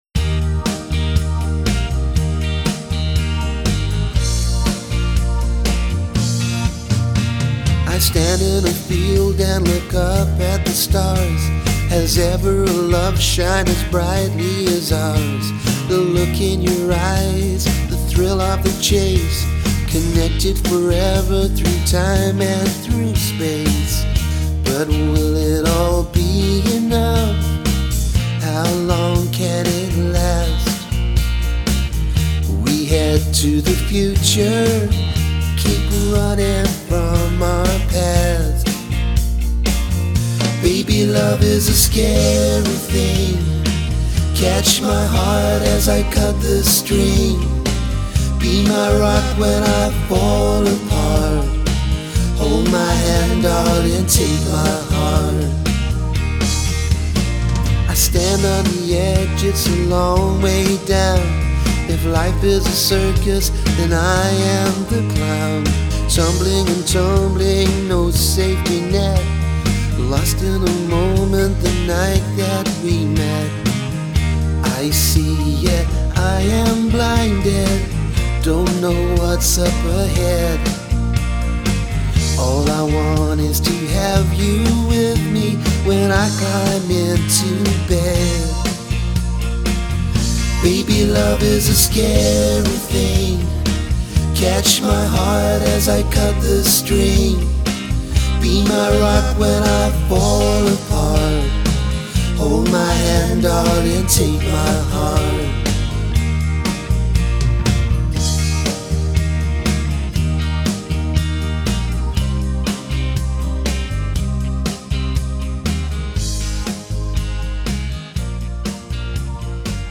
It is very upbeat and makes me happy!
The music was fun and mellow.
Very engaging, with a little old school twist to it.
• The song was really simple and laid back to listen to.
Very mellow, the lyrics were good the tune upbeat.